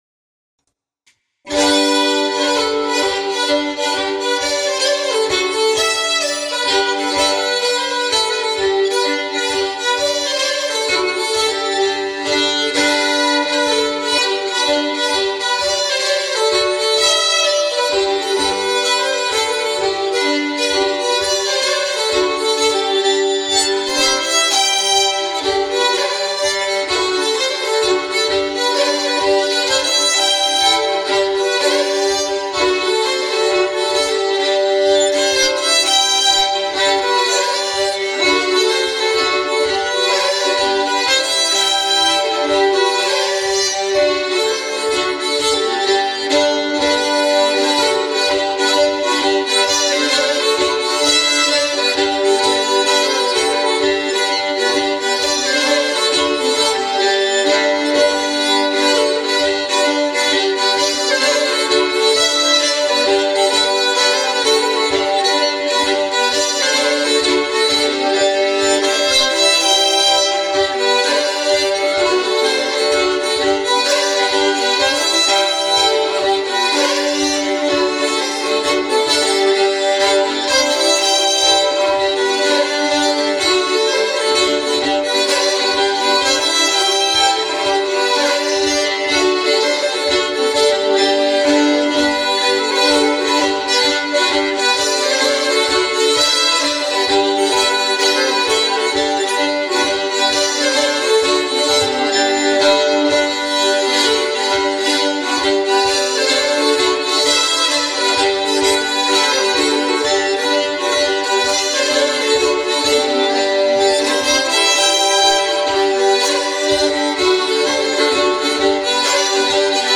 Riksspelman på Svensk Säckpipa
Jag spelar en polska efter en spelman ifrån norra Karlskoga i Kedjeåsen.